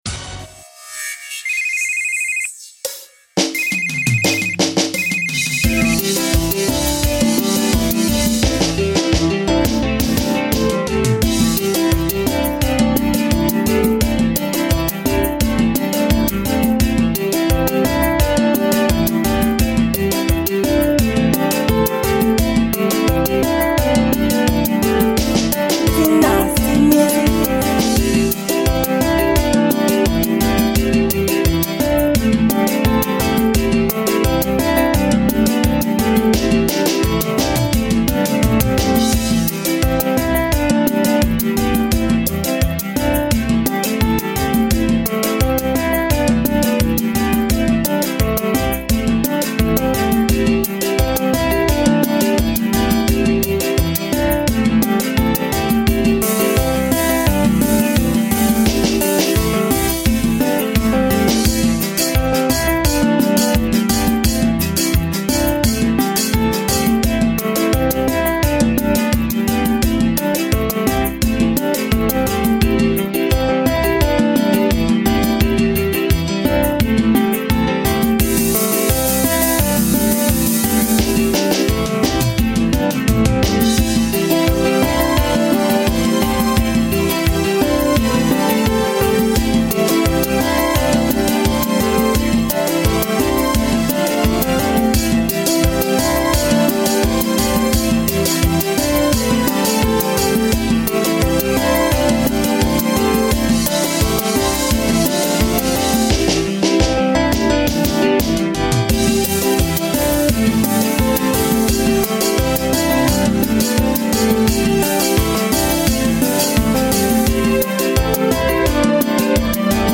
Genre: Beat.